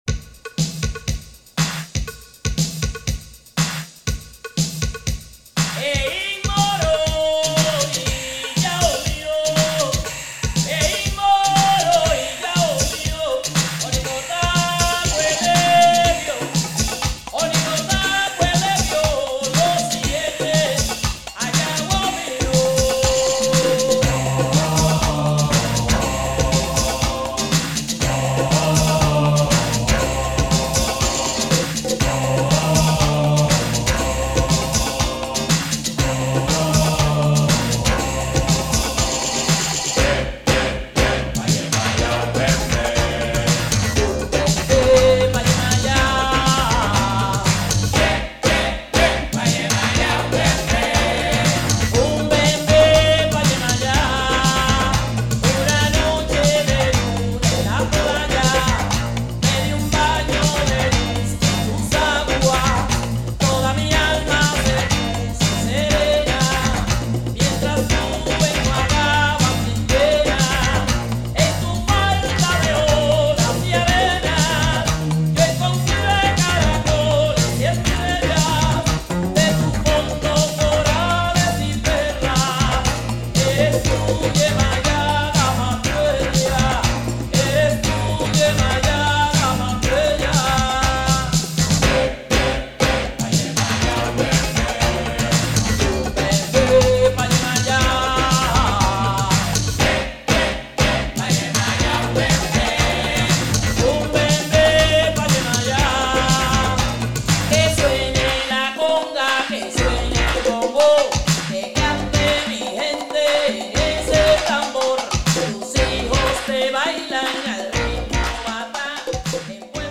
Dancefloor alert !